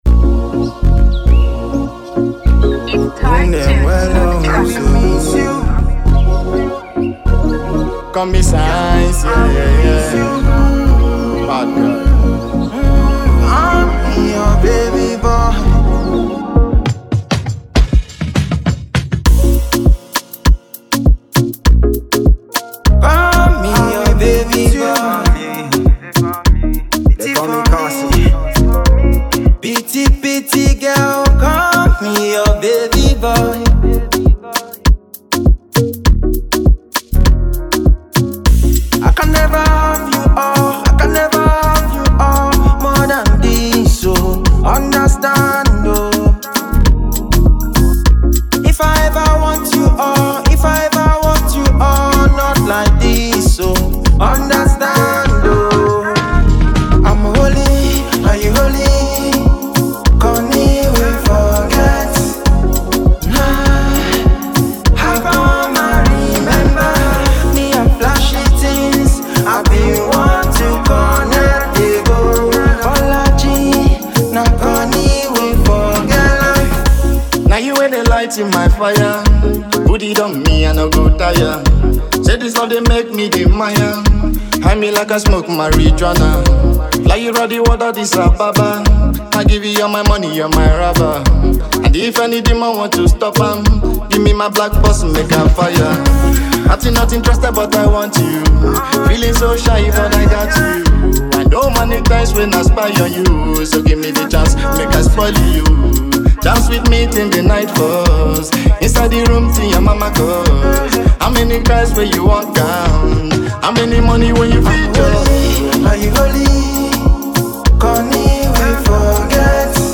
combines Afrobeat influences with modern soundscapes
Packed with energy and emotion
vibrant melodies and heartfelt lyrics
Fans of Afrobeat, pop